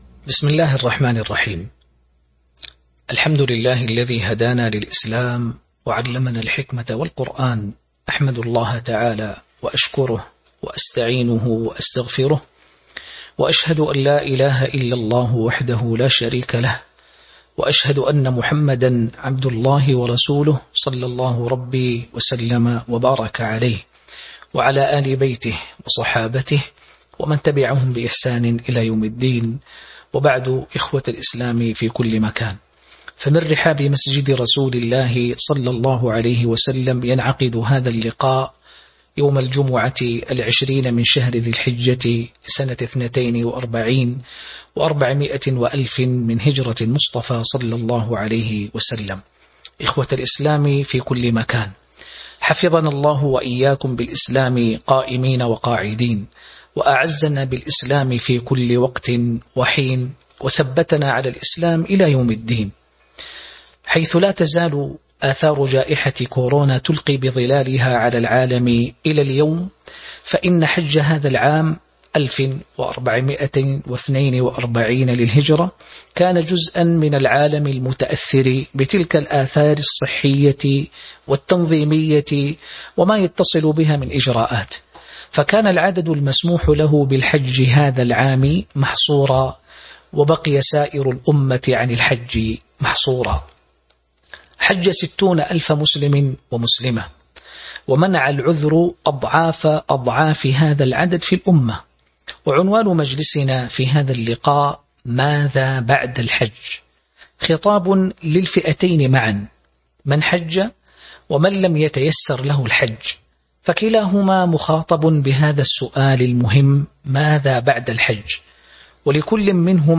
تاريخ النشر ٢٠ ذو الحجة ١٤٤٢ هـ المكان: المسجد النبوي الشيخ